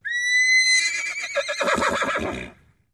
Whinnies
Horse Whinnies & Blows 4